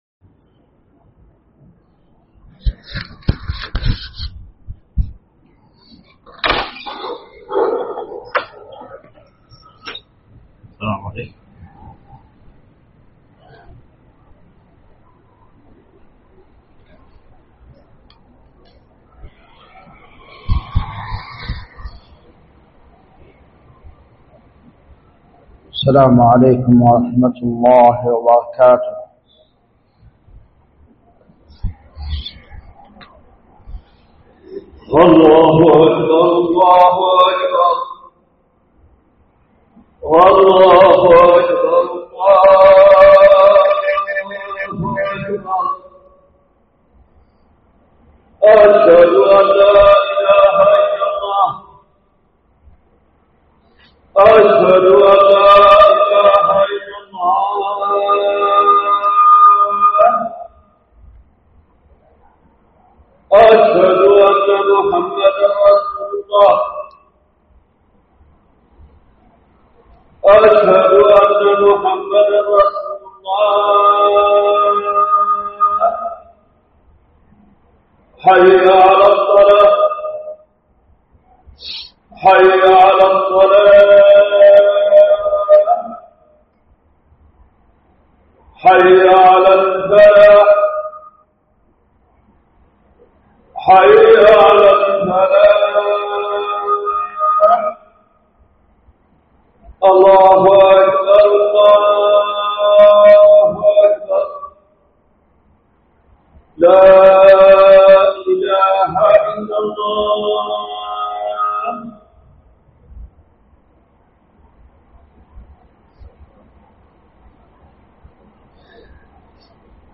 جامع الملك عبدالعزيز باسكان الخارش بصامطة
سماع الخطبة